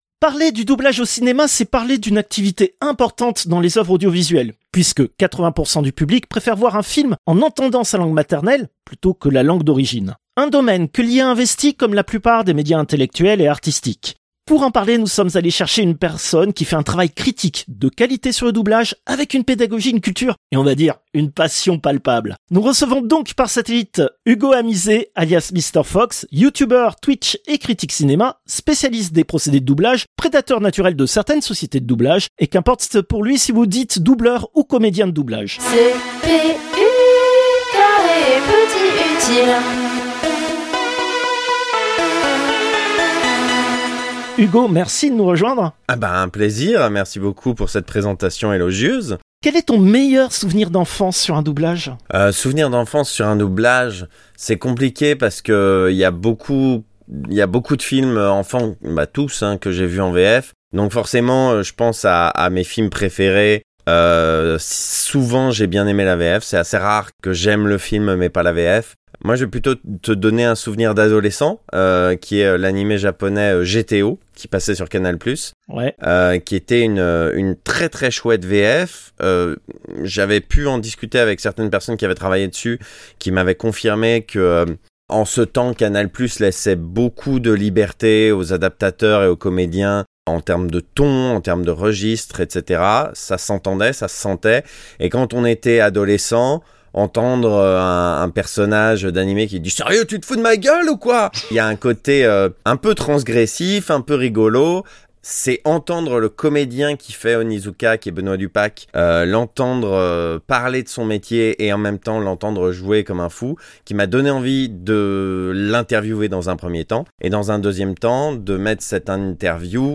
Interviewes
Interview diffusée dans les émissions CPU releases Ex0235 : Doublage robot, première partie et CPU release Ex0236 : Doublage robot, seconde partie.